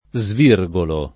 svirgolo [ @ v & r g olo ]